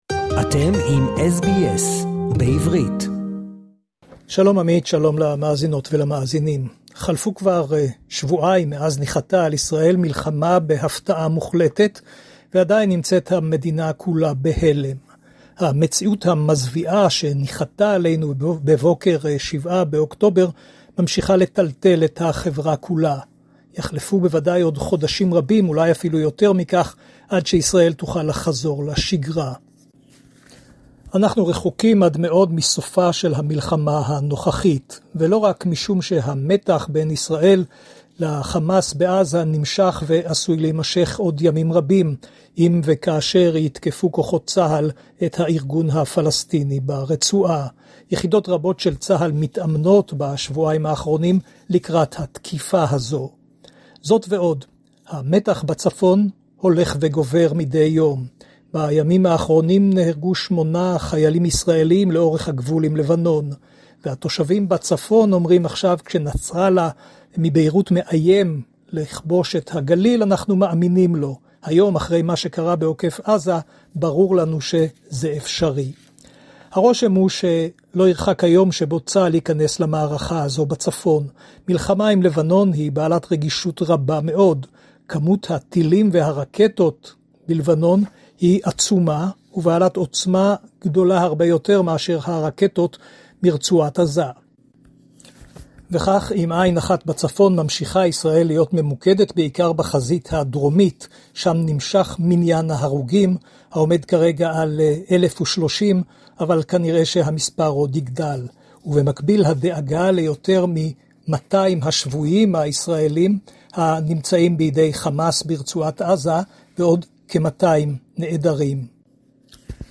Israel In Its Second Week Of The War - Special Report